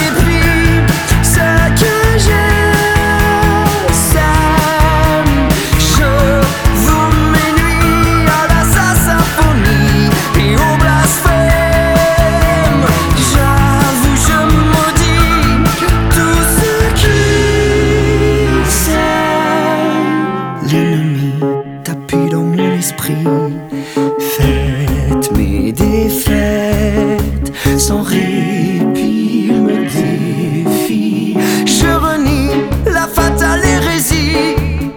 Жанр: Поп музыка / Рок / Соундтрэки